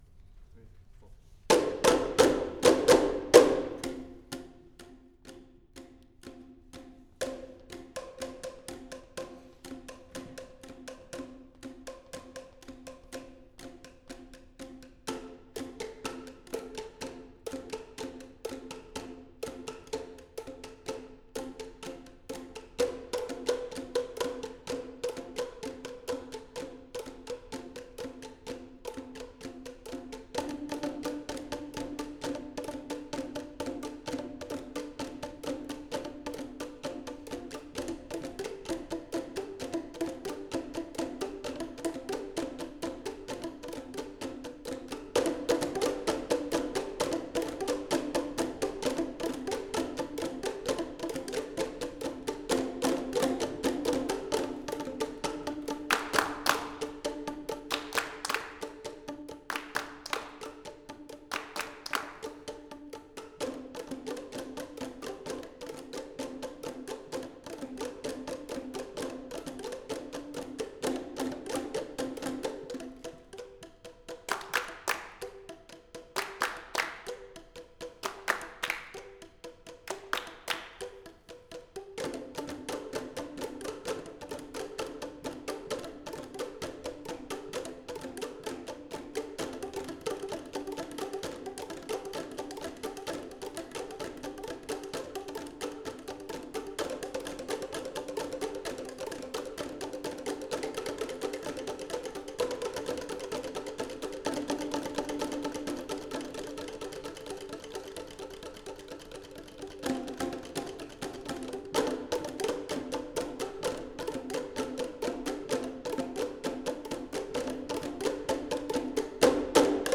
Bamboom – Andrew Smith       UVM Percussion Ensemble F19
Bamboom is based on that music, and is written for Boomwhackers (brightly colored musical tubes that produce a definite pitch).